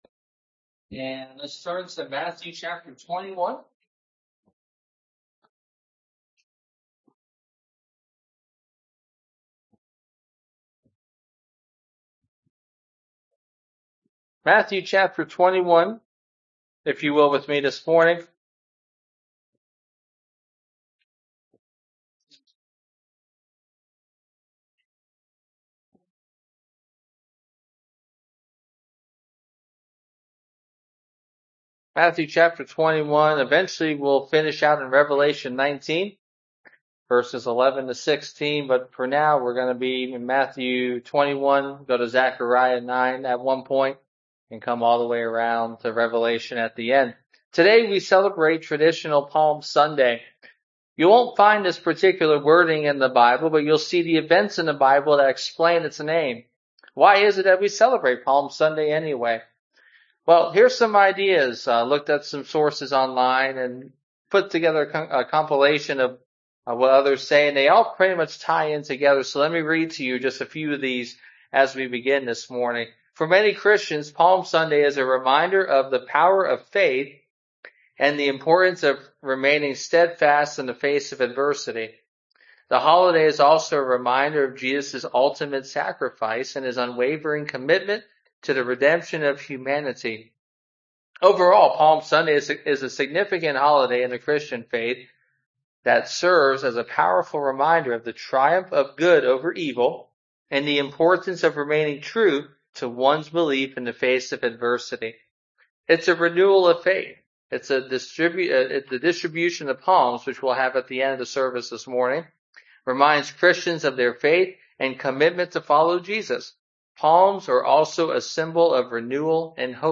Exposition of Matthew Passage: Matthew 21:1-17 Service Type: Sunday Morning (voice only) Download Files Bulletin « Your Gift